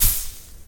cig_snuff.ogg